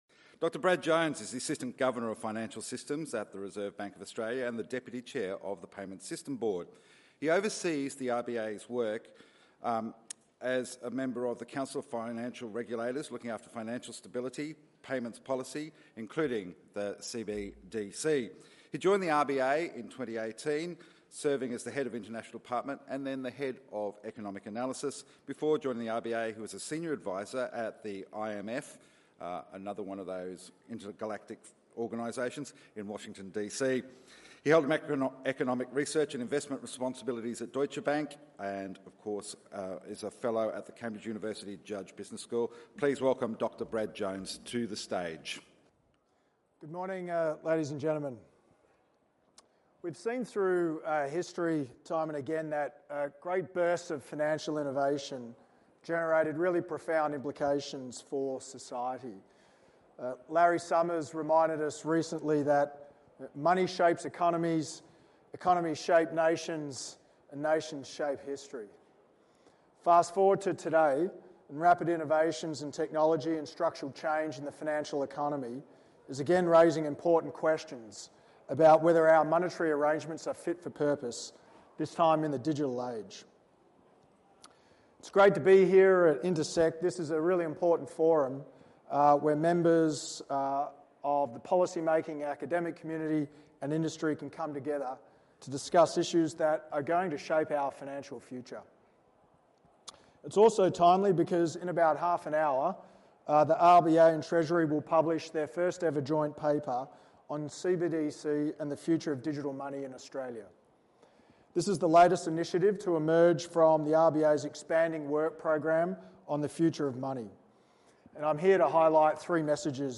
Speech delivered by Brad Jones, Assistant Governor (Financial System), at the Intersekt Conference, Melbourne
Speech at the Intersekt Conference Melbourne – 18 September 2024